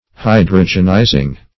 Search Result for " hydrogenizing" : The Collaborative International Dictionary of English v.0.48: Hydrogenize \Hy"dro*gen*ize\, v. t. [imp.
hydrogenizing.mp3